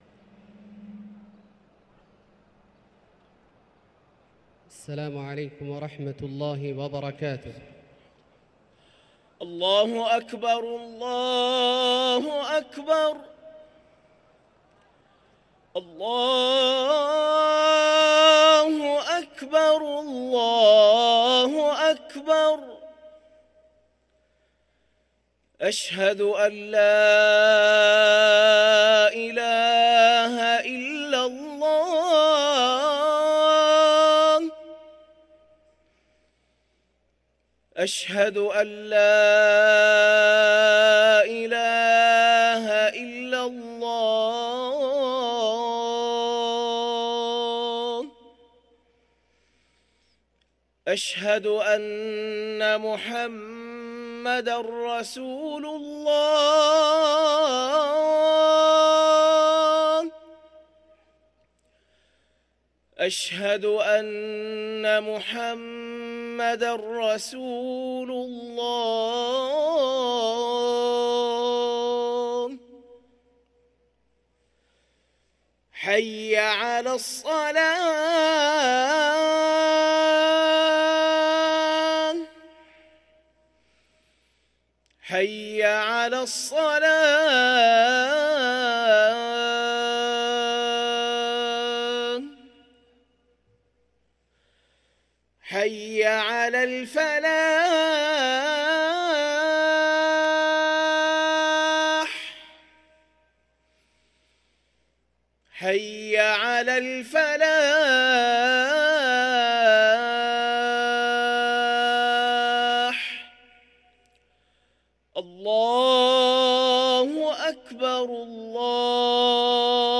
أذان الجمعة الثاني
ركن الأذان